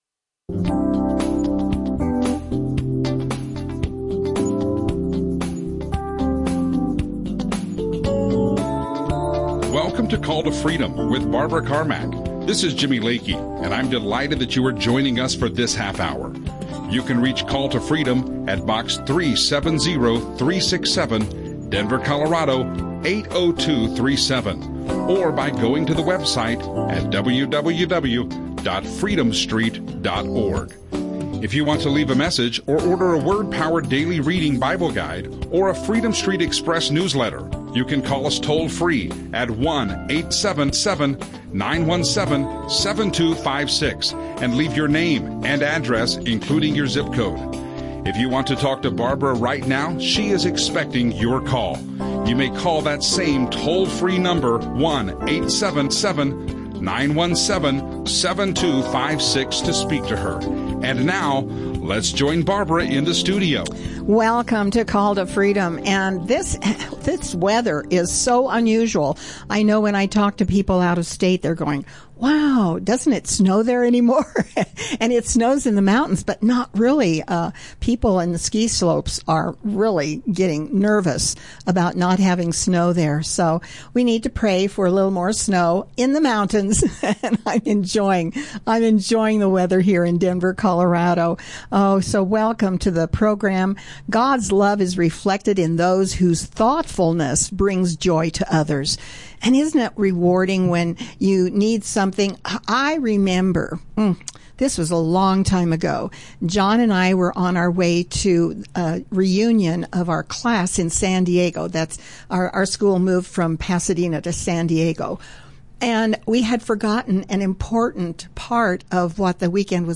Audio teachings
Call to Freedom Christ-centered community Christian healing ministry Christian radio Christian resources Colorado ministry Decree a Thing...